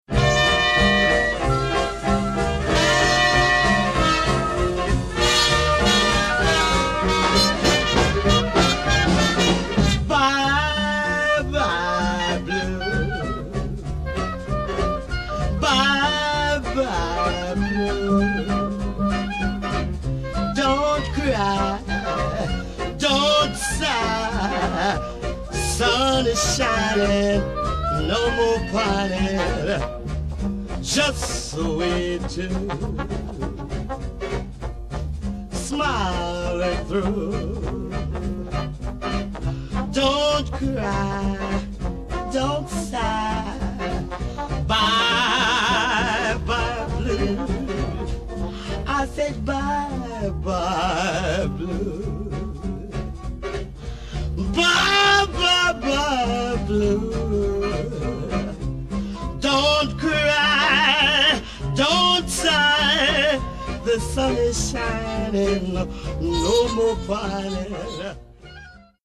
at Dixieland Hall
trombone
clarinet
drums
on the banjo.